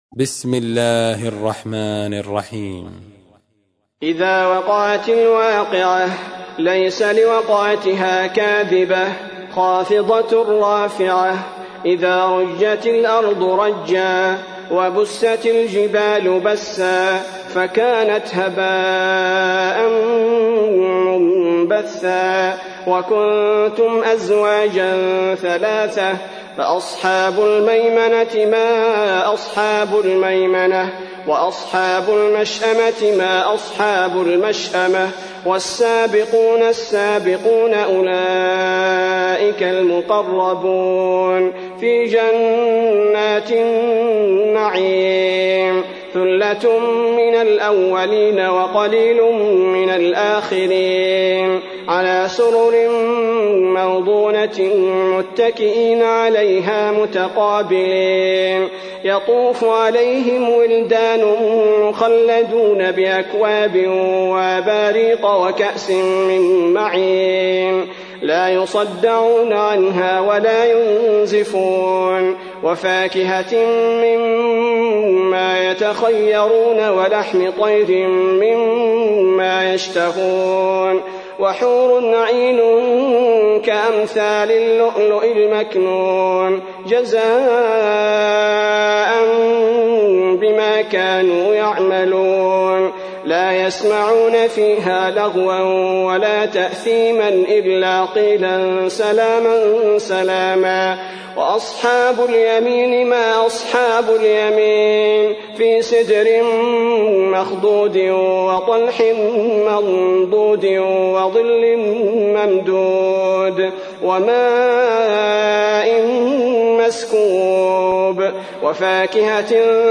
تحميل : 56. سورة الواقعة / القارئ عبد البارئ الثبيتي / القرآن الكريم / موقع يا حسين